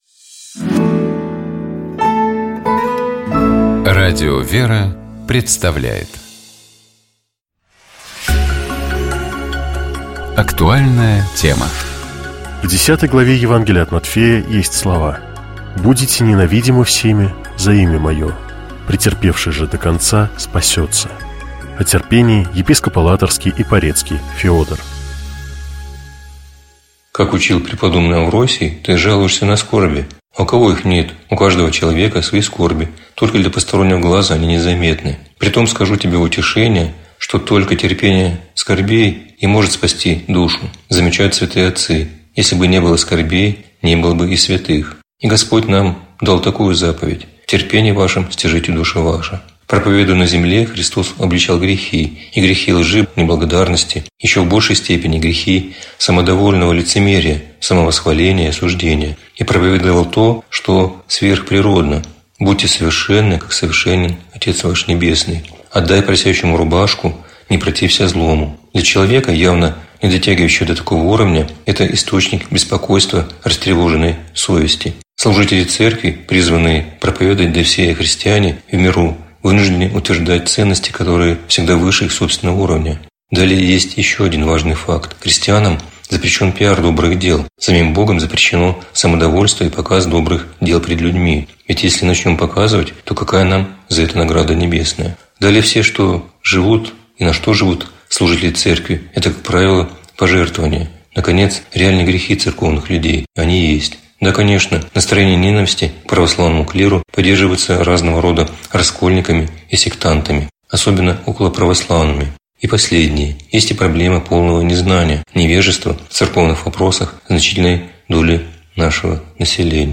О терпении, — епископ Алатырский и Порецкий Феодор.